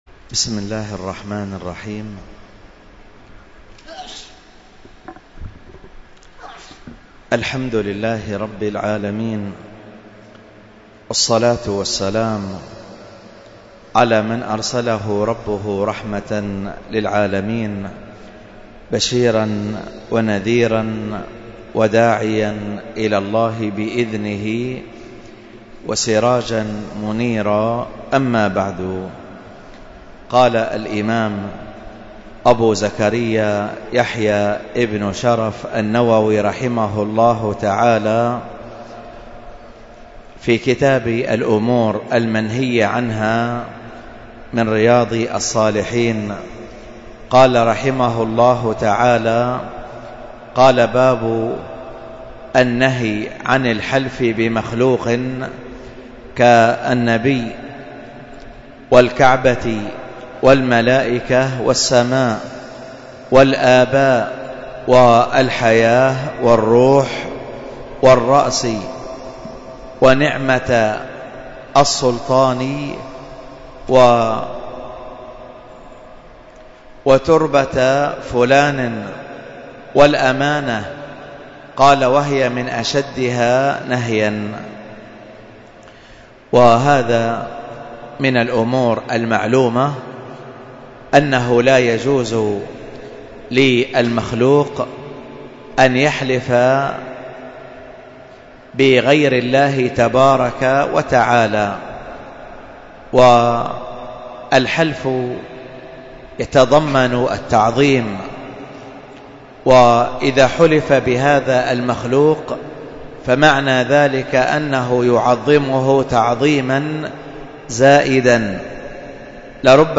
الدرس في كتاب الطهارة 42، ألقاها